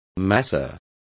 Complete with pronunciation of the translation of masseur.